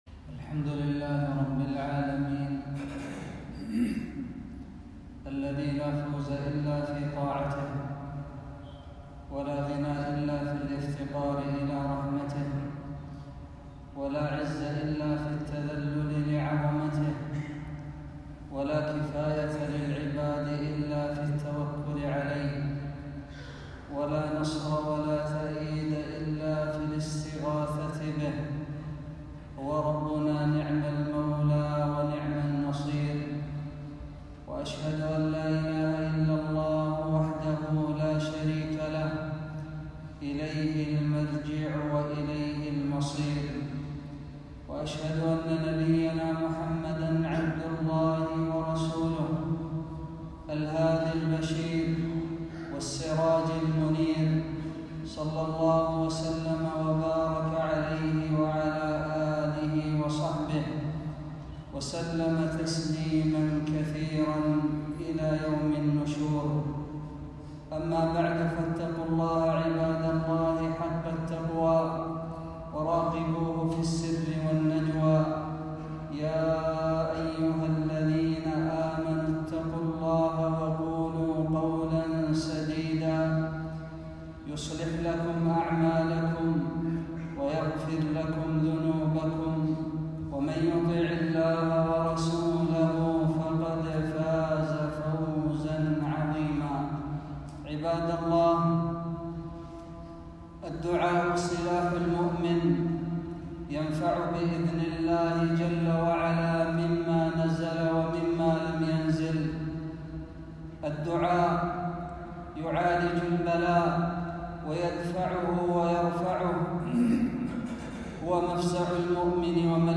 خطبة - الاستنصار الدعاء